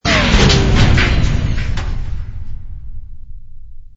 tractor_complete.wav